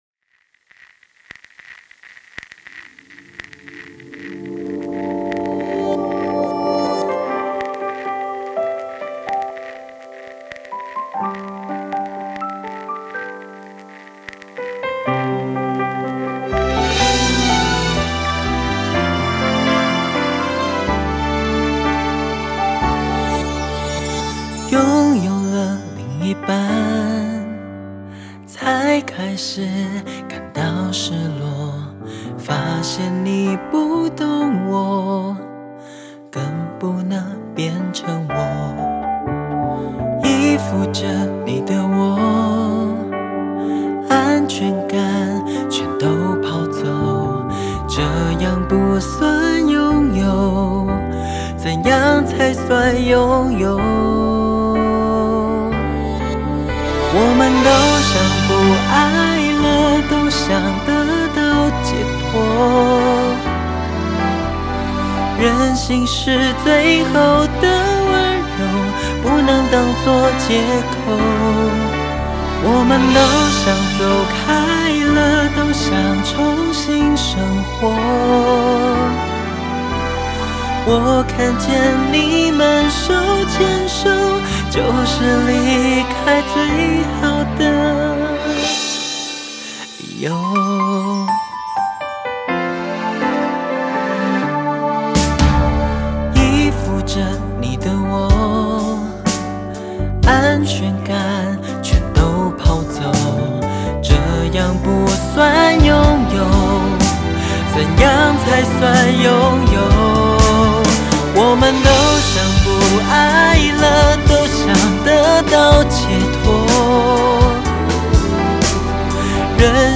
温暖的男生 华语音乐希望的诞生
温柔主打